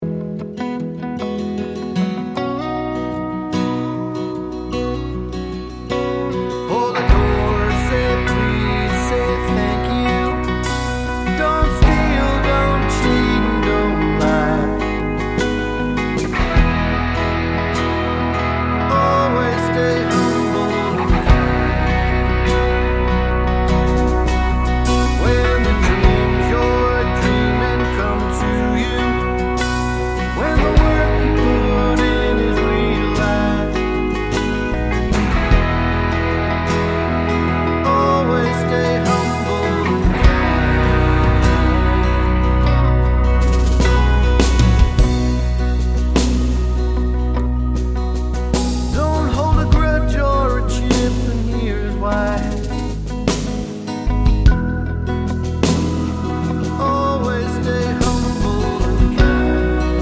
no Backing Vocals Country (Male) 4:15 Buy £1.50